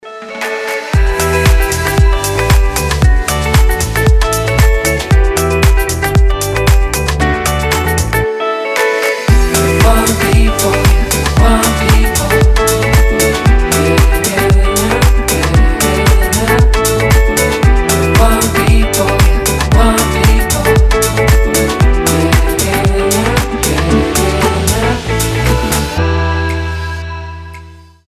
• Качество: 320, Stereo
гитара
deep house
мелодичные
EDM
tropical house
летние
теплые